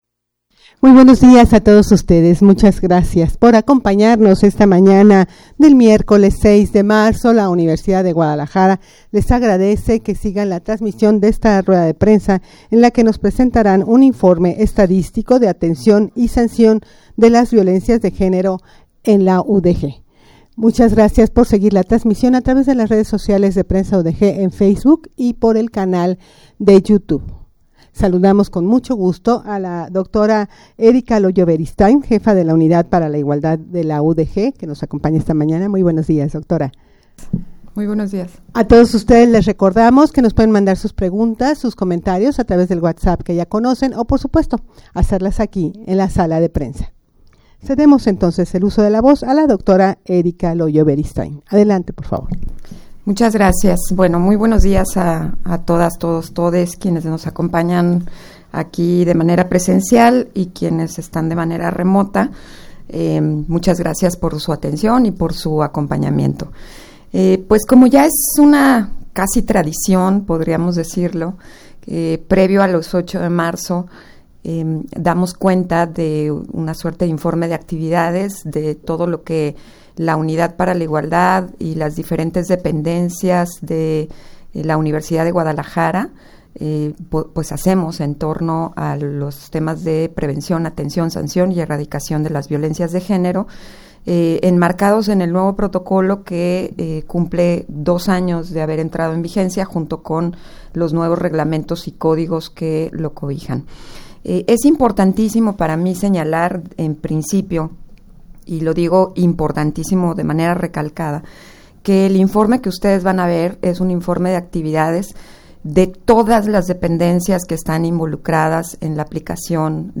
rueda-de-prensa-informe-estadistico-de-atencion-y-sancion-de-las-violencias-de-genero-en-la-udeg.mp3